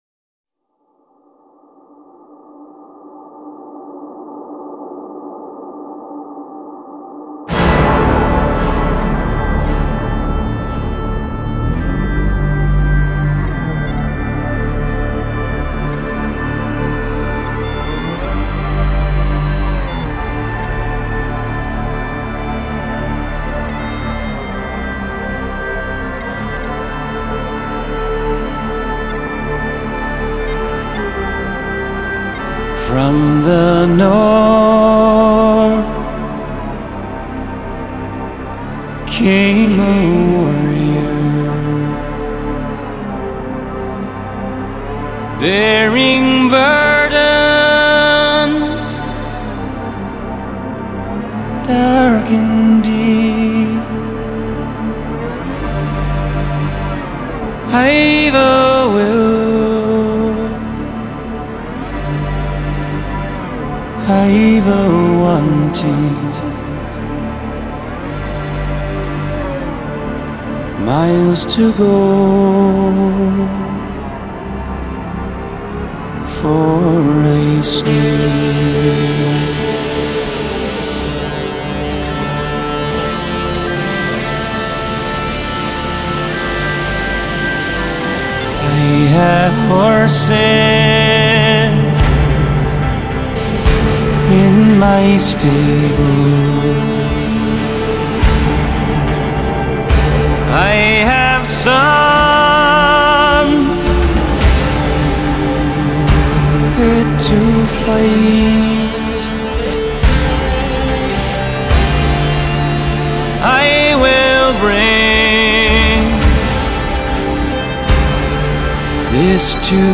Style: Folk-Rock